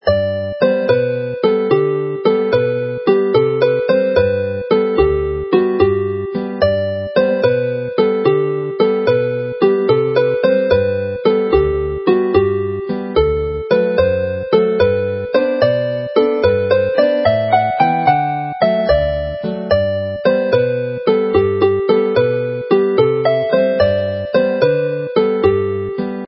Chwarae'r alaw'n araf